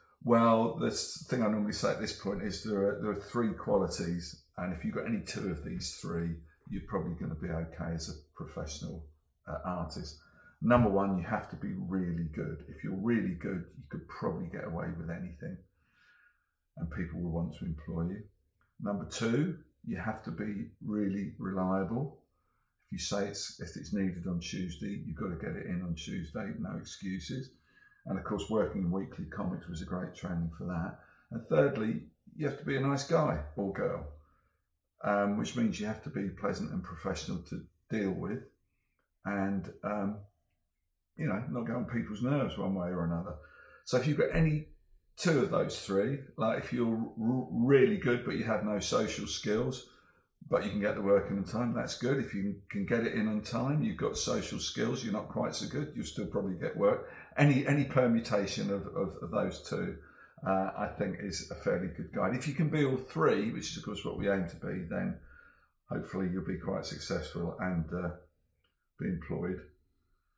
Dave Gibbons interview: What three tips would you give to aspiring comic artists?